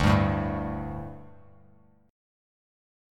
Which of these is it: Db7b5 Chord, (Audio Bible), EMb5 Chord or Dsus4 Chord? Dsus4 Chord